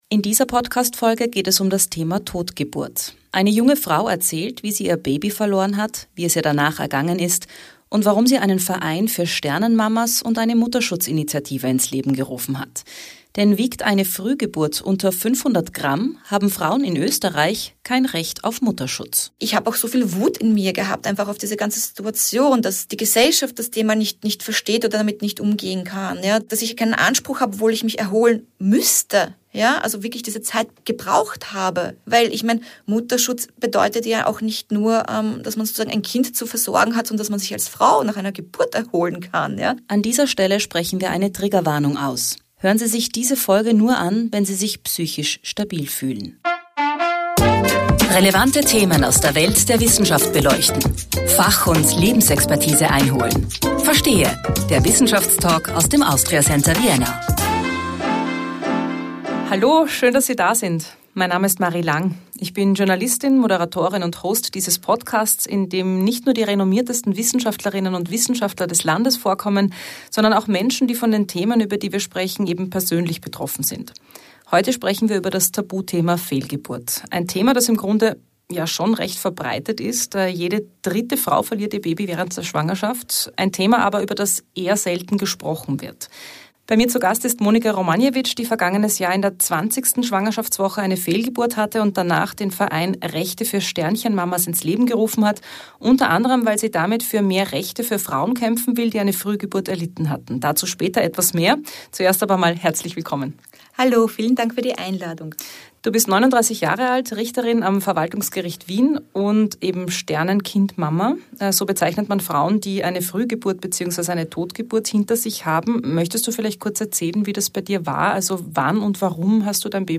In dieser Folge geht es um das Thema Todgeburt. Eine junge Frau erzählt, wie sie ihr Baby verloren hat, wie es ihr danach ergangen ist und warum sie einen Verein für Sternenmamas und eine Mutterschutzinitiative ins Leben gerufen hat.